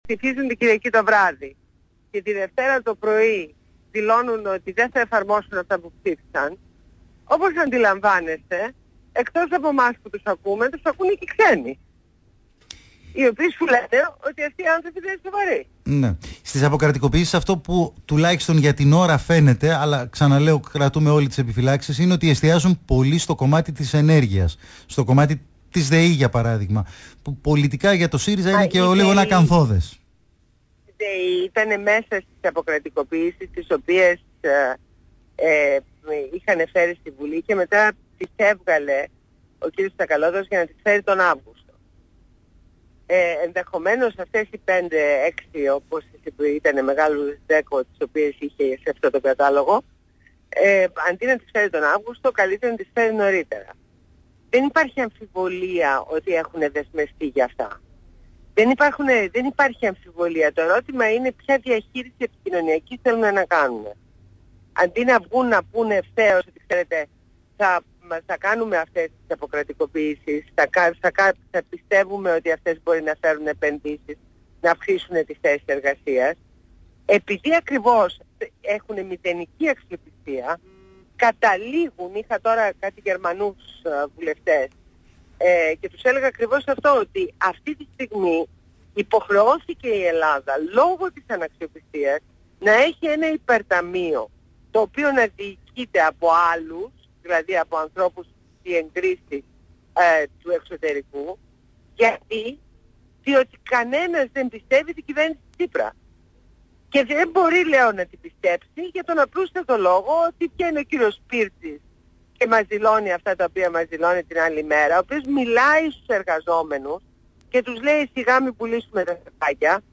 Συνέντευξη στα Παραπολιτικά fm